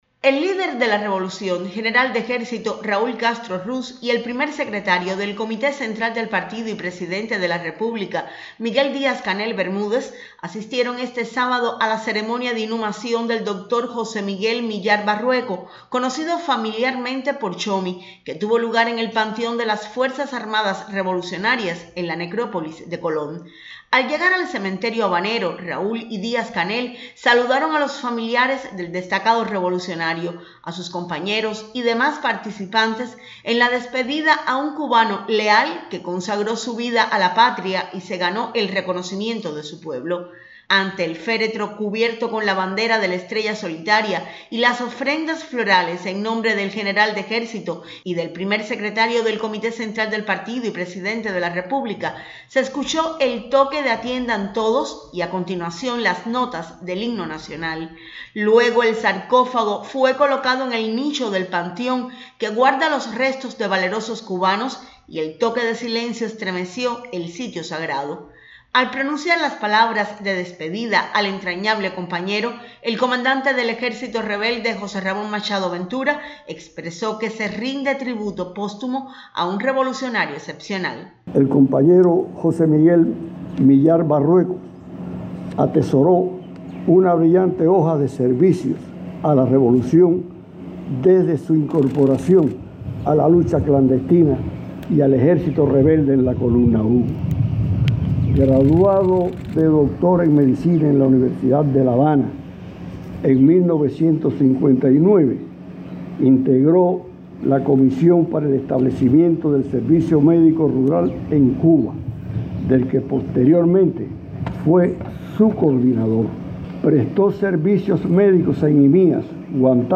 Asisten Raúl y Díaz-Canel a ceremonia de inhumación del destacado revolucionario José Miyar Barrueco (+Audio)